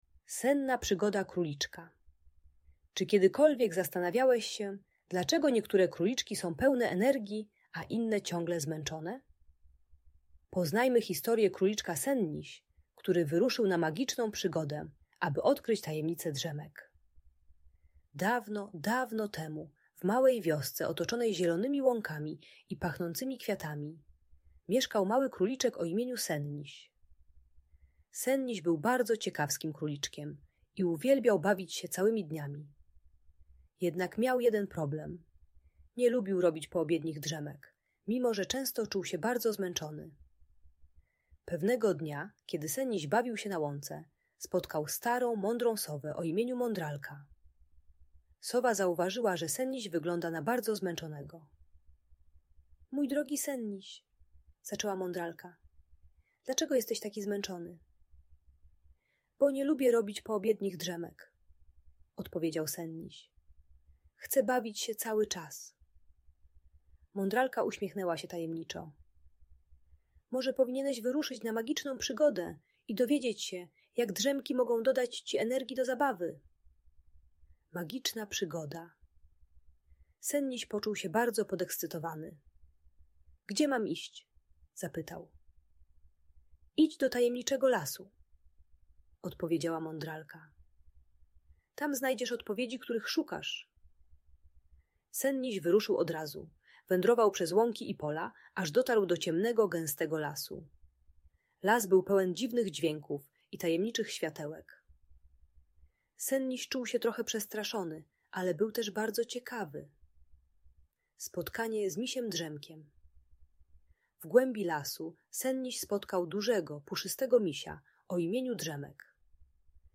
Przygoda Króliczka Senniś - Magiczna historia pełna energii - Audiobajka dla dzieci